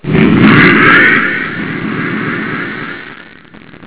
jump1.wav